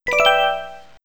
Écoutons d’abord les sons joués lorsque le joueur s’empare d’une ressource sur la carte.
Certains sons, plus stridents font penser à une pluie d’or, tandis que d’autres ont une profondeur (grâce à la réverbération et la longueur du son) que l’on ne peut trouver que dans certaines pierres précieuses ; certains font penser à une forme brute et anguleuse (avec une attaque très marquée) tandis que d’autres ont une forme de rondeur et de douceur qui peuvent évoquer la perle.